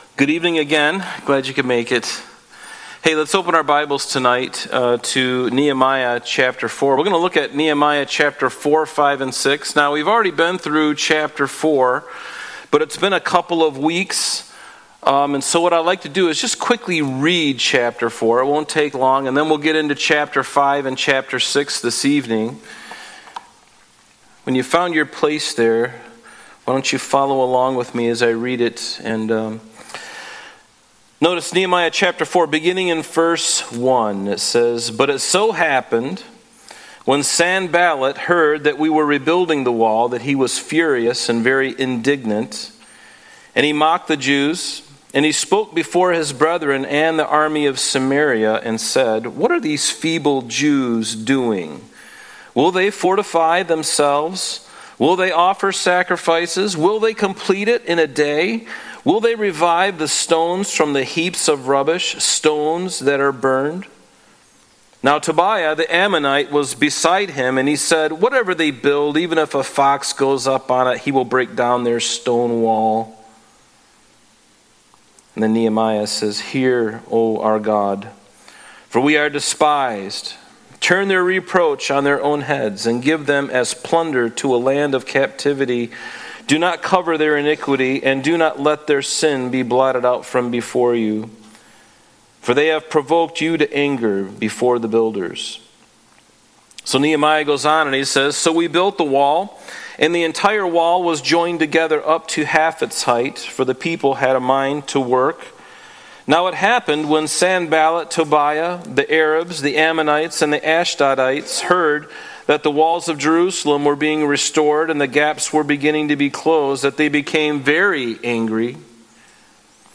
Thursday Night Bible Study